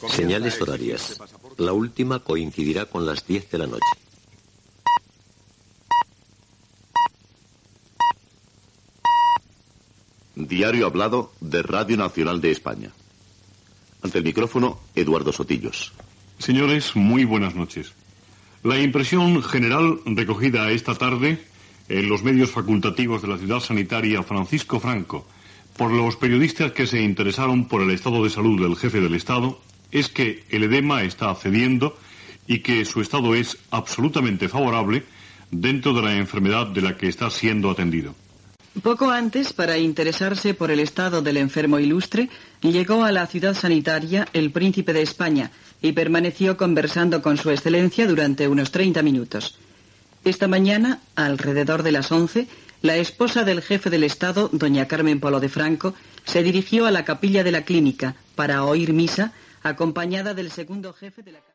Senyals horaris, identificació, informació de l'estat de salut del "Generalísimo" Francisco Franco que està ingressat a la Ciudad Sanitaria de Madrid.
Informatiu